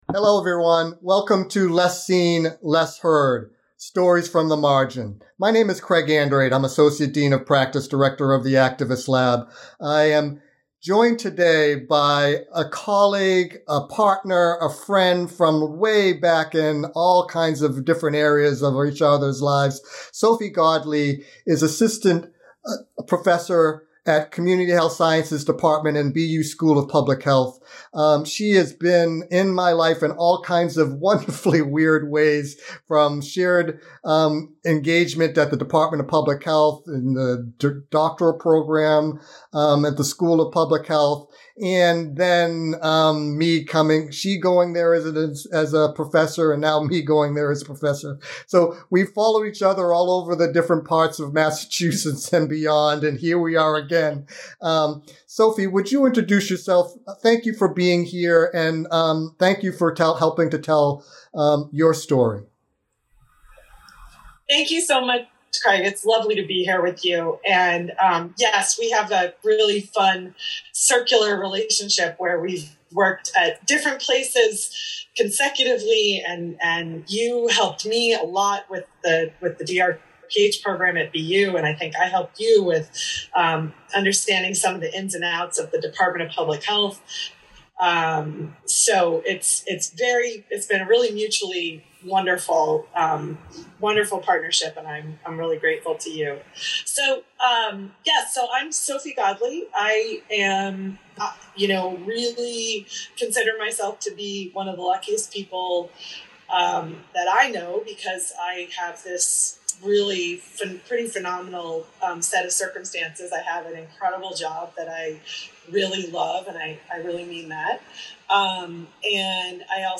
Listen to the full conversation here or read the transcript.